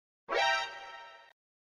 Play Metal-Alerta - SoundBoardGuy
Play, download and share Metal-Alerta original sound button!!!!
alerta-2s.mp3